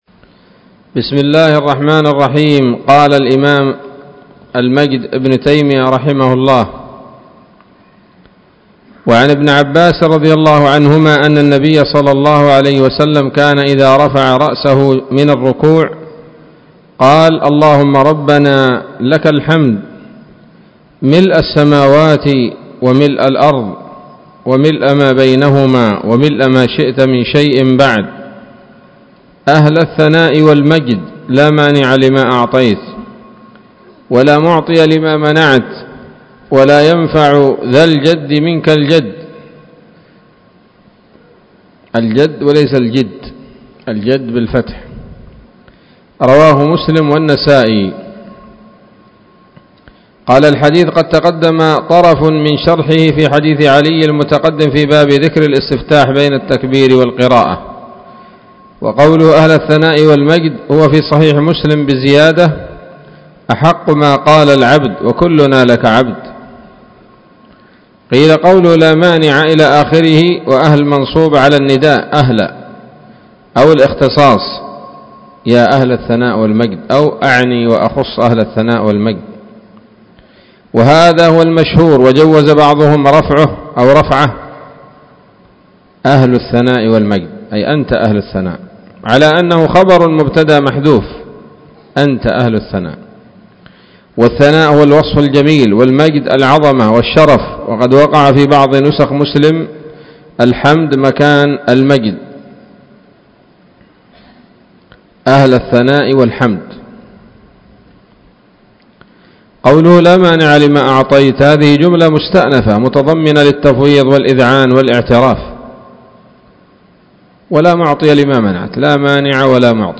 الدرس التاسع والخمسون من أبواب صفة الصلاة من نيل الأوطار